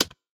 bonus_click.ogg